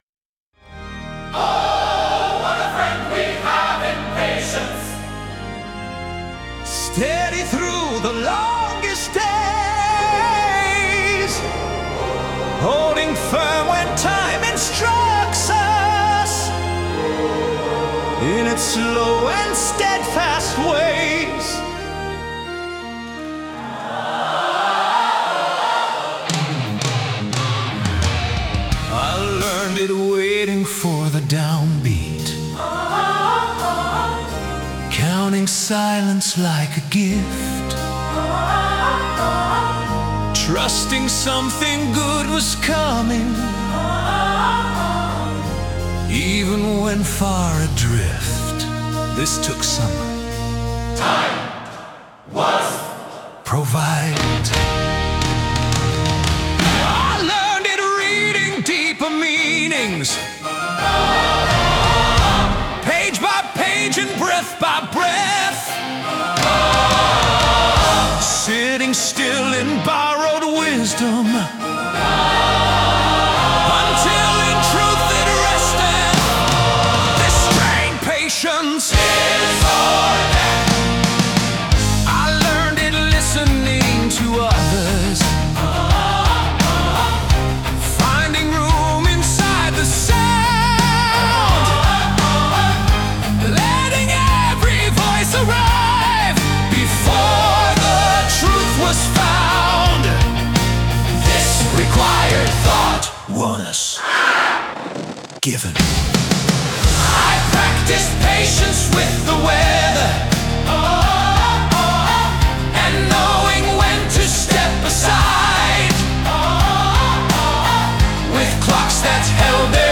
Meditative · Emergence